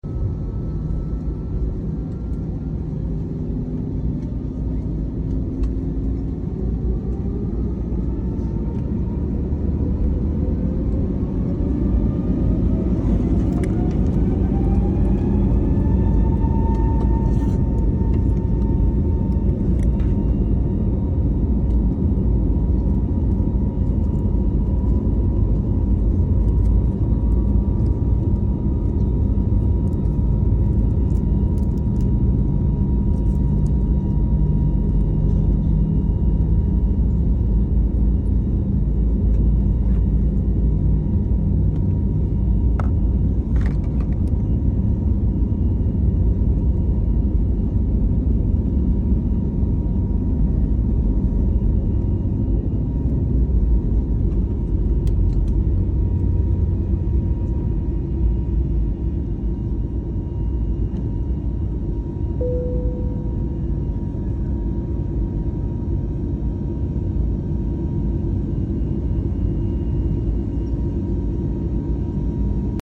Stopping at the beginning of the runway then accelerating full speed is always so fun too filled with anticipation and exhilaration!